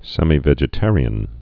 (sĕmē-vĕjĭ-târē-ən, sĕmī-)